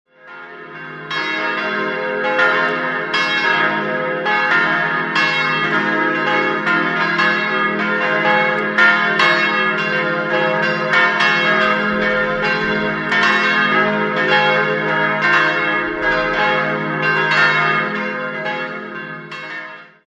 4-stimmiges TeDeum-Geläute: es'-f'-as'-c'' Die Glocken wurden 1964 von der Gießerei Perner in Passau gegossen.